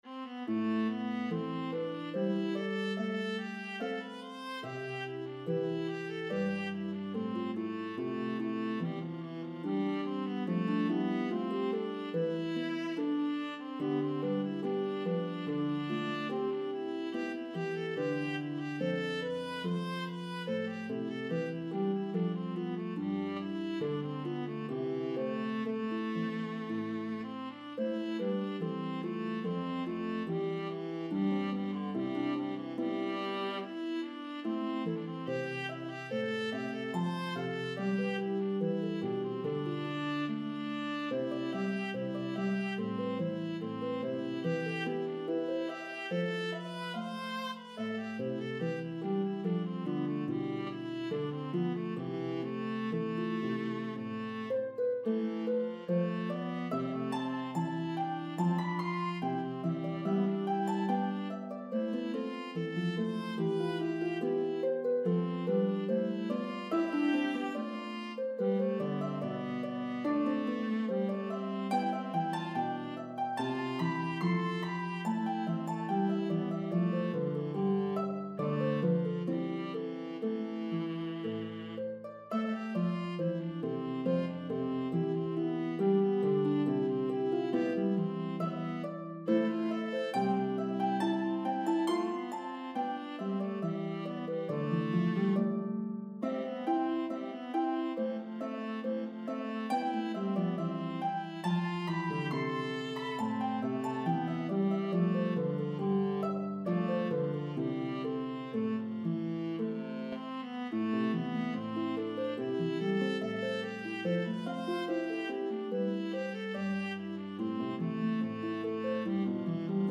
This beautiful Aeolian melody has a debated history.
Italian Baroque piece